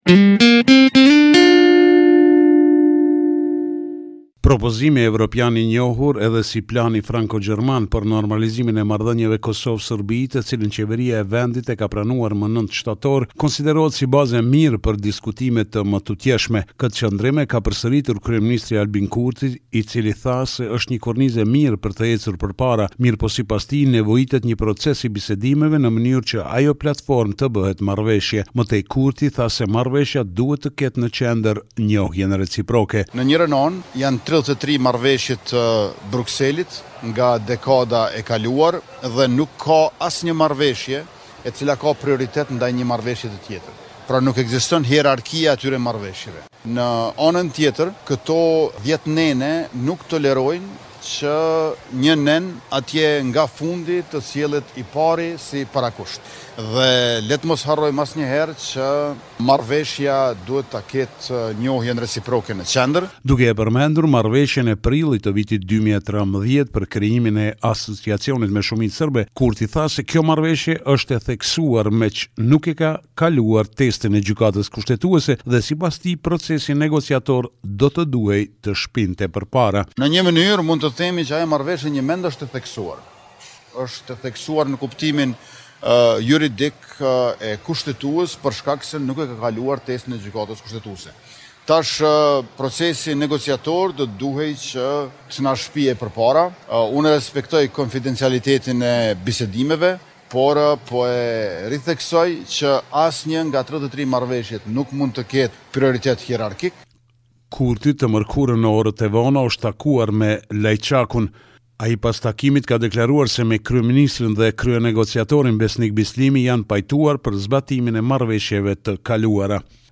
Raporti me të rejat më të fundit nga Kosova.